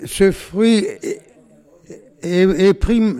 Localisation Olonne-sur-Mer
Catégorie Locution